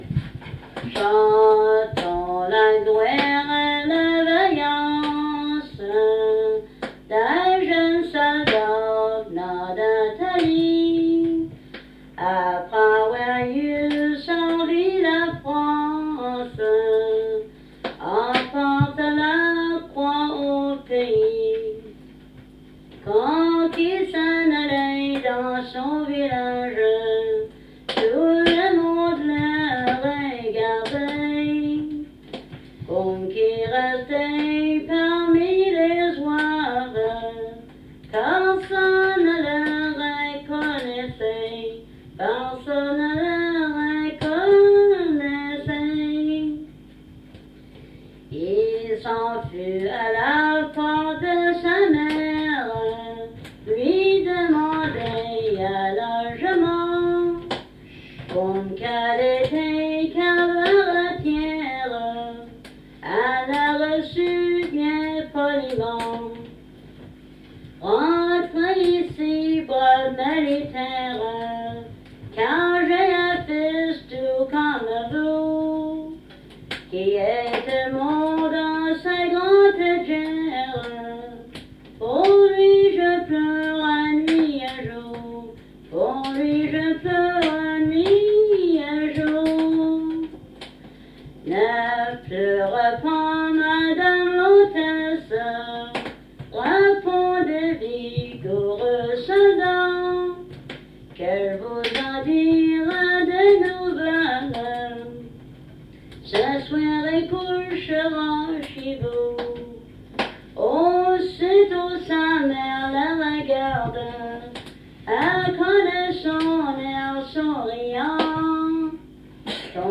Chanson Item Type Metadata
Emplacement Cap St-Georges